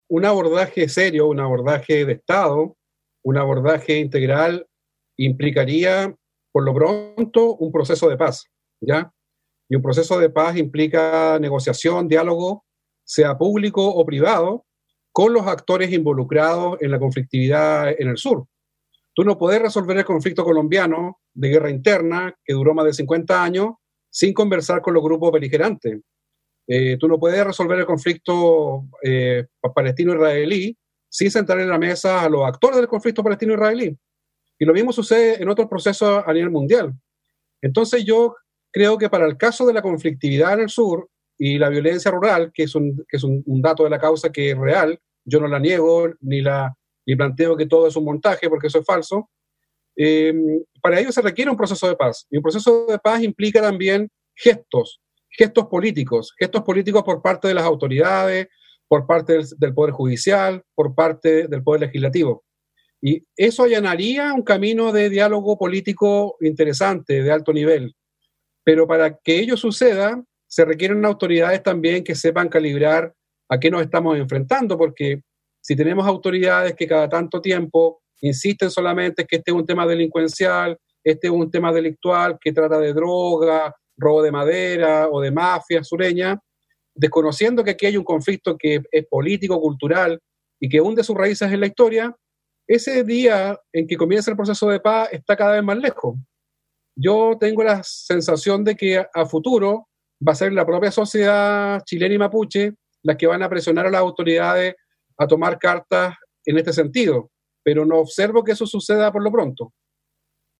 En entrevista con Zoom Constitucional